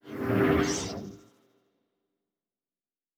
pgs/Assets/Audio/Sci-Fi Sounds/Doors and Portals/Teleport 7_1.wav at 7452e70b8c5ad2f7daae623e1a952eb18c9caab4
Teleport 7_1.wav